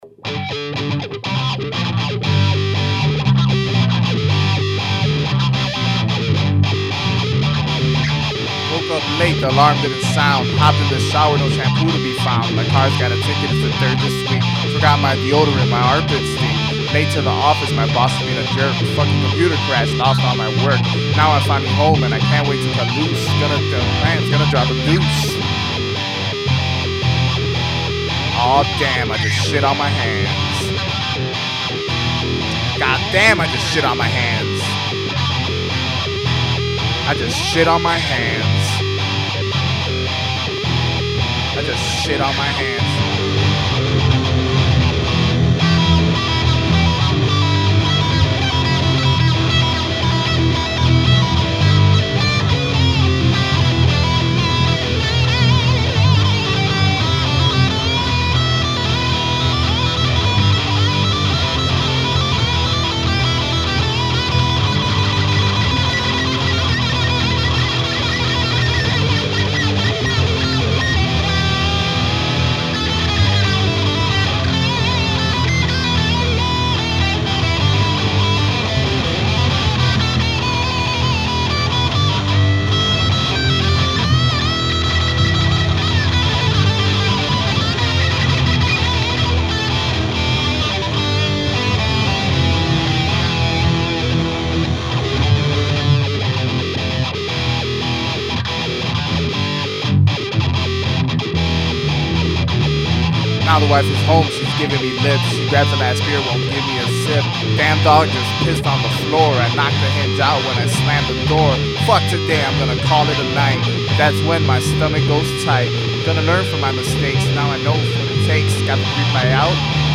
guitar
piano, cello and bass. Words and vocals